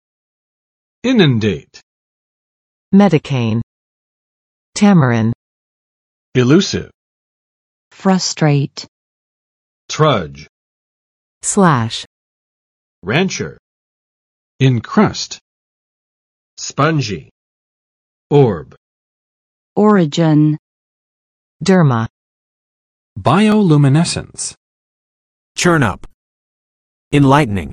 [ˋɪnʌn͵det] v. 浸水；泛滥